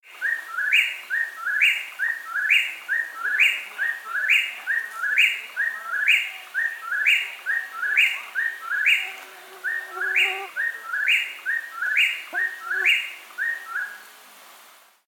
File:Antrostomus vociferus - Eastern Whip-poor-will - XC103418.ogg - HandWiki